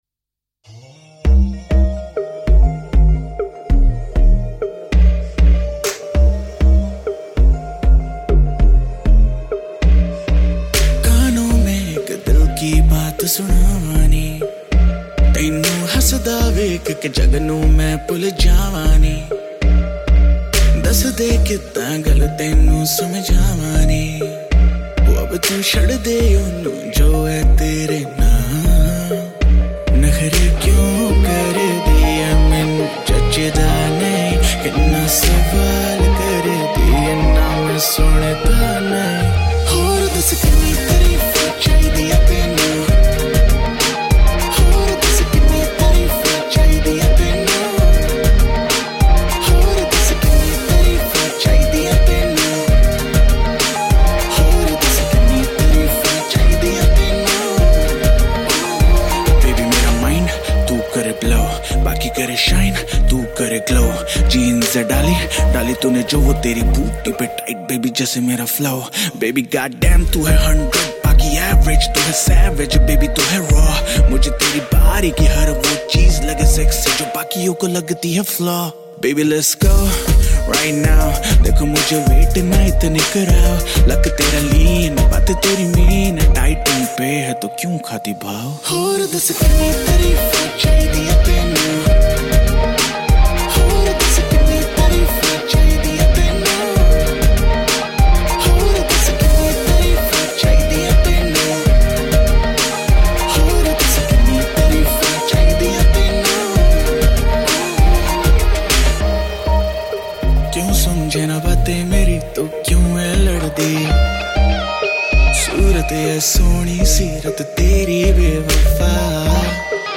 Pop, Rock